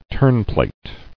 [terne·plate]